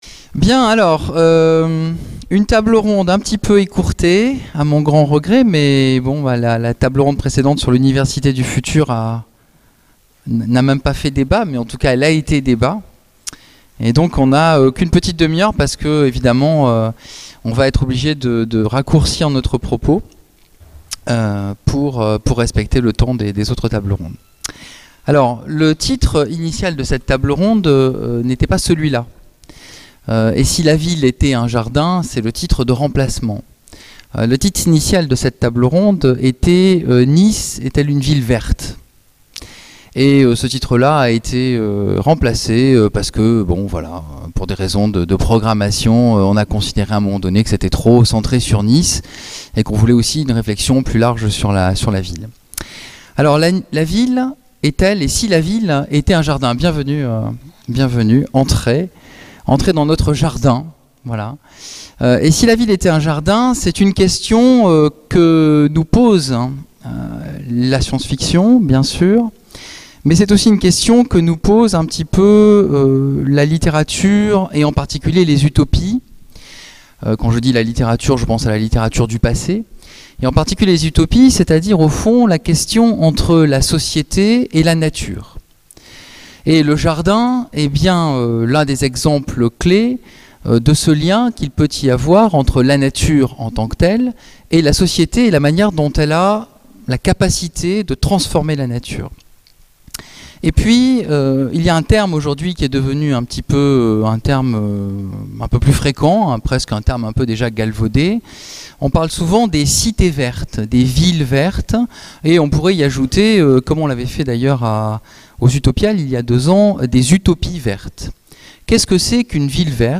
Nice fiction 2015 : Table ronde Et si la ville était un jardin
Conférence